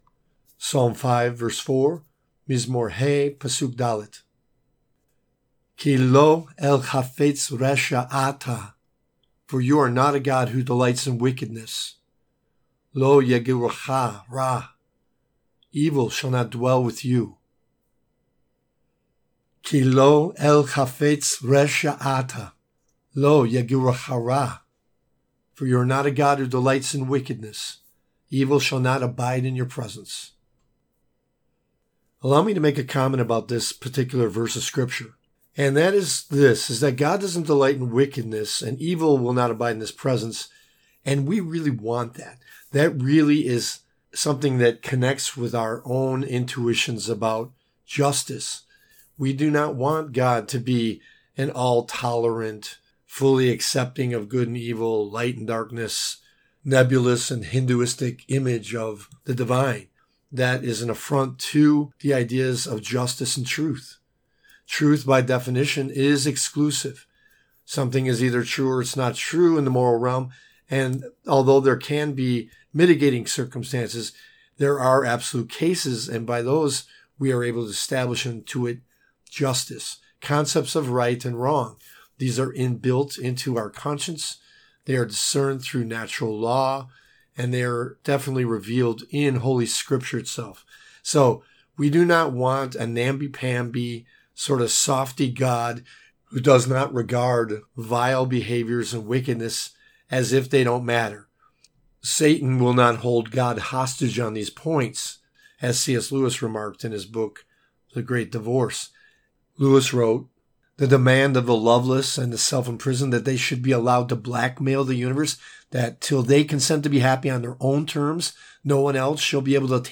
Psalm 5:4 reading (click for comments):
Psalm 5:4 Hebrew lesson